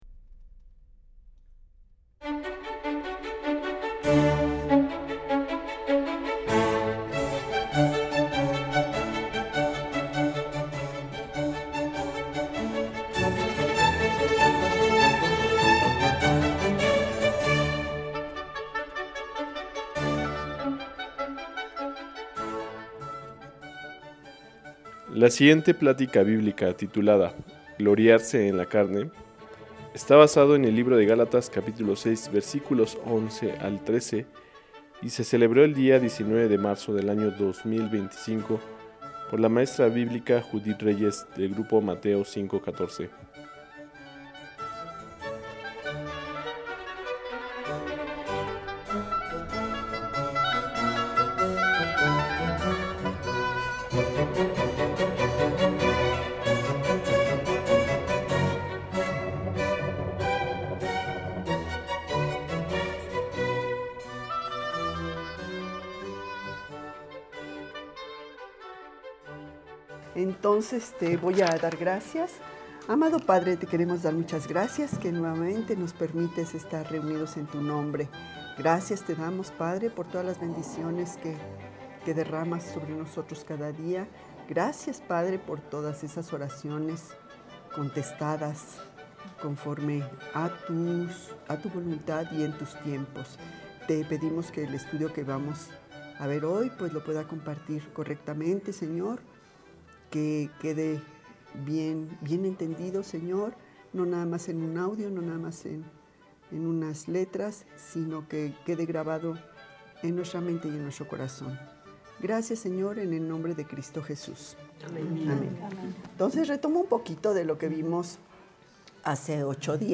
2025 Gloriarse en la Carne Preacher